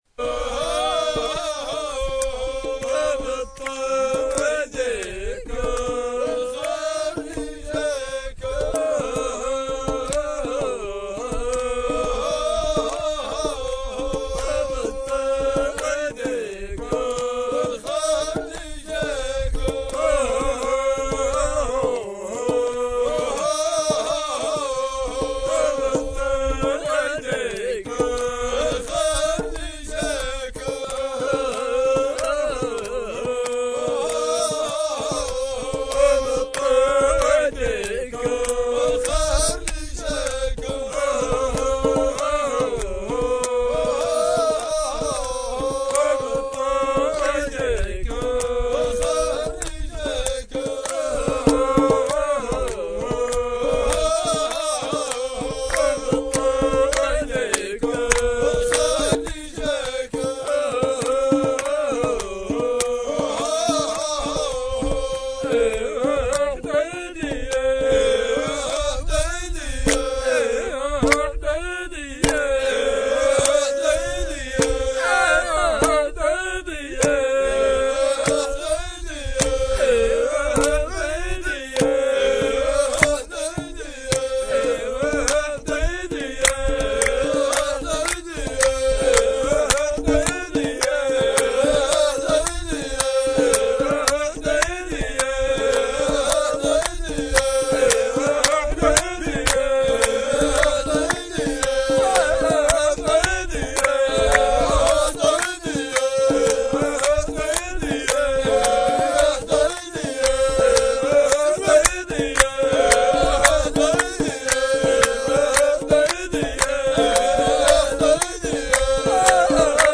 Chants traditionnels sahraouis
1 - Rythme GUEDRA